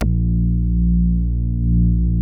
P.5 A#2 8.wav